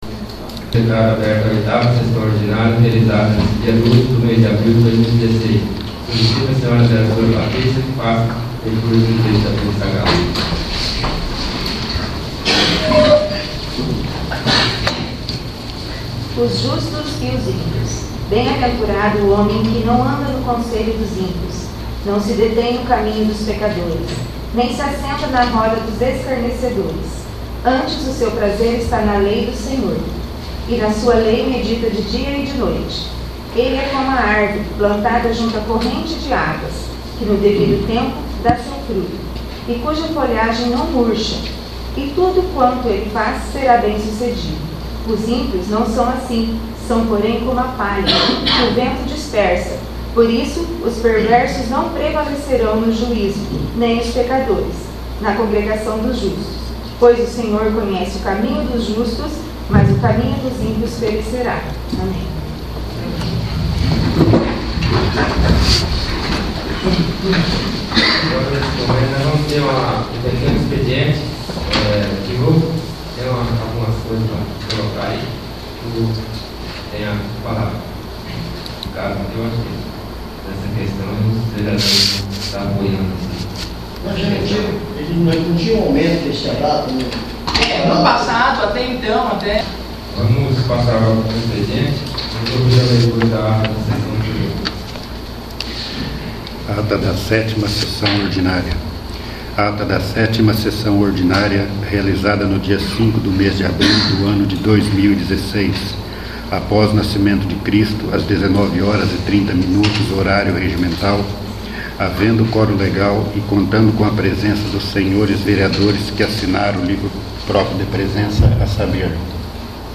8º. Sessão Ordinária